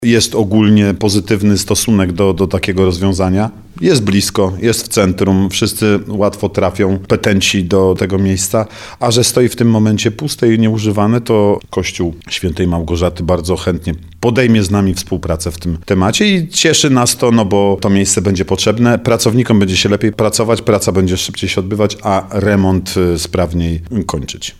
Zastępca prezydenta Nowego Sącza Artur Bochenek informuje, że miasto rozmawiało już z właścicielem budynku, czyli parafią św. Małgorzaty.